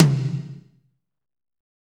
Index of /90_sSampleCDs/Northstar - Drumscapes Roland/DRM_Fast Rock/TOM_F_R Toms x
TOM F RHI0NR.wav